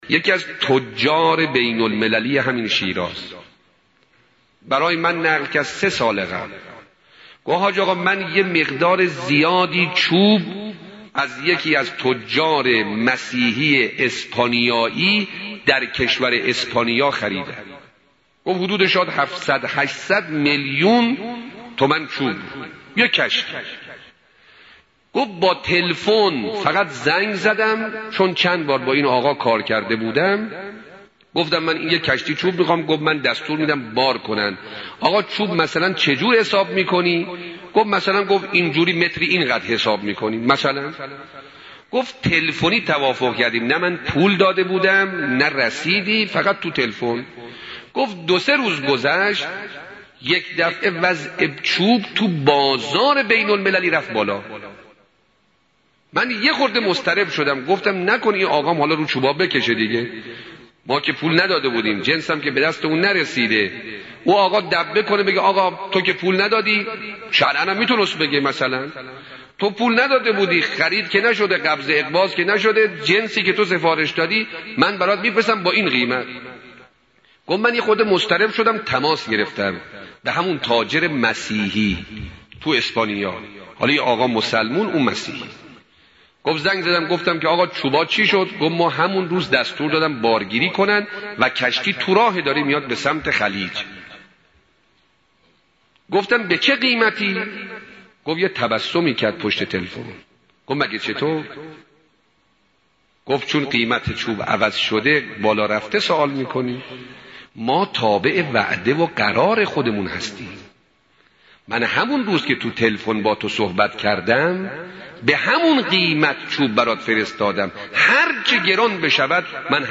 سخنرانی اموزنده